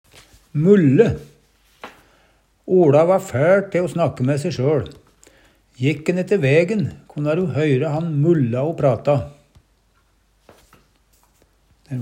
mulle - Numedalsmål (en-US)